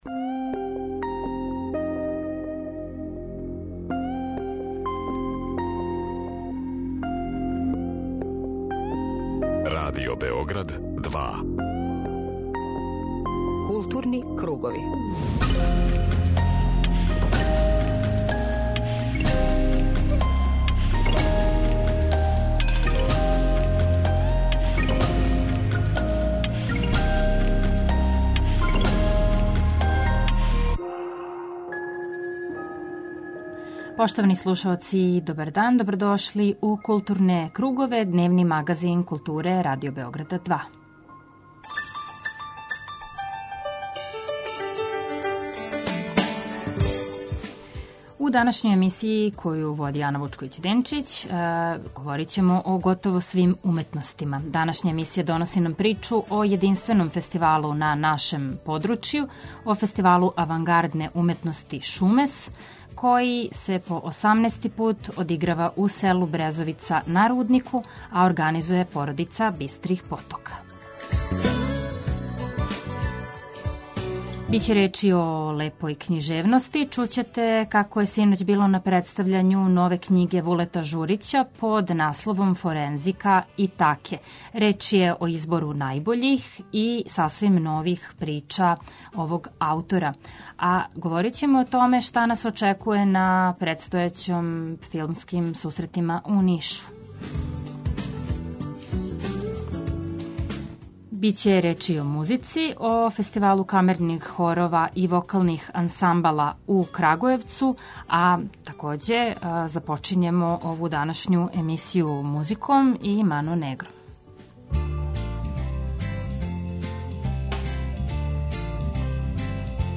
Магазин културе Радио Београда 2